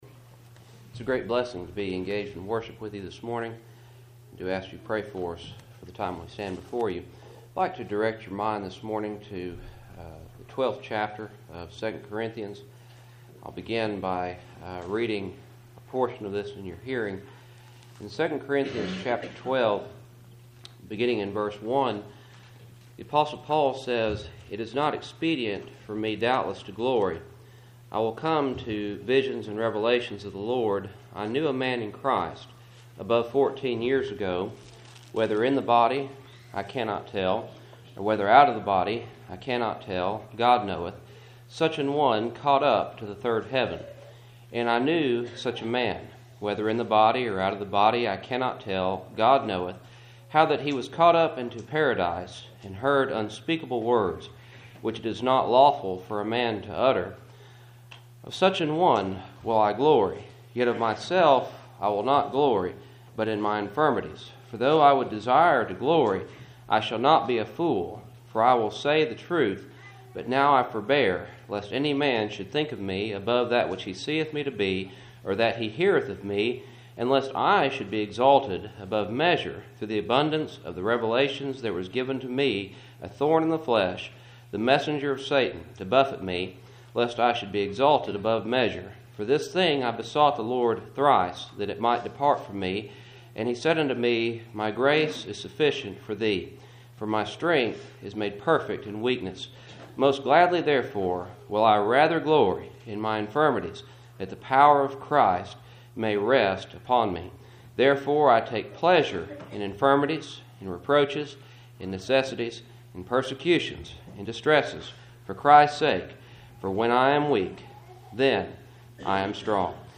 Passage: 2 Corinthians 12:1-10 Service Type: Cool Springs PBC Sunday Morning